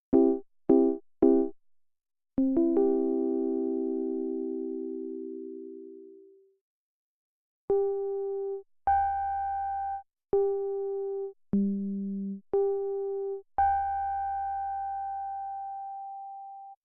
But still little instabilities getting in the way. It starts getting pretty inharmonic higher up in the range (which is where FM pianos shine, IMHO). Also, while the multimode filter seems to track the keyboard much more accurately, I can’t seem to dial in a cutoff exactly equal to whatever frequency the tuning-fork-LFO is locked to, so everything goes flat in the sustain.